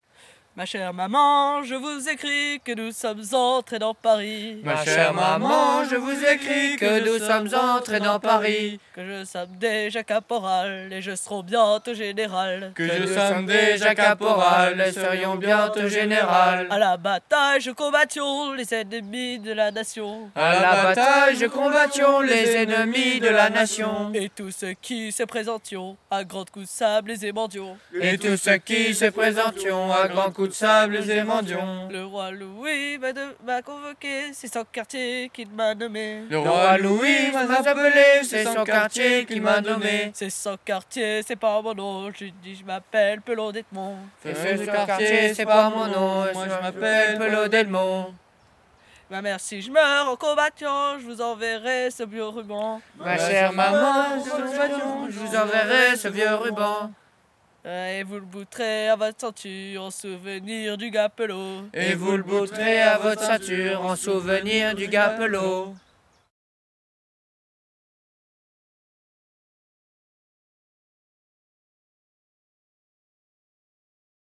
Genre : chant
Type : chant de mouvement de jeunesse
Interprète(s) : Les Scouts d'Europe d'Arlon
Lieu d'enregistrement : Arlon
Chanté à la veillée.